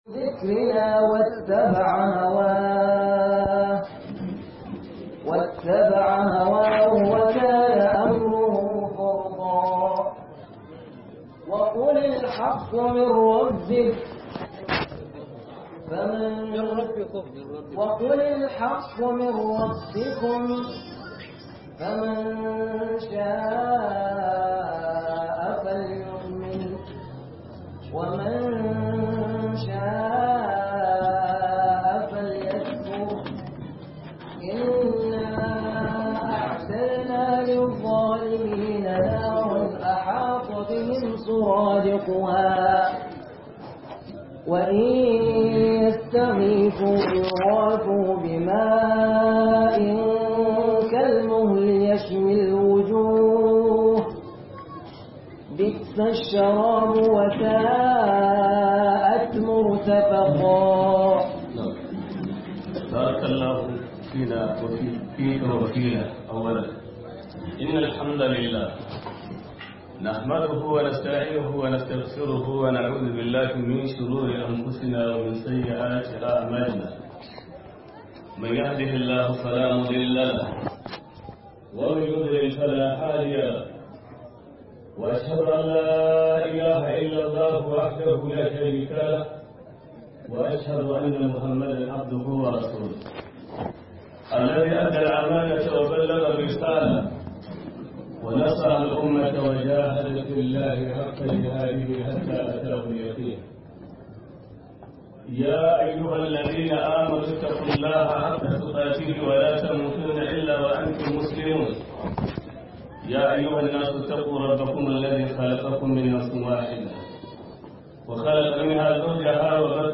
واجب الأمة لهلمائها - MUHADARA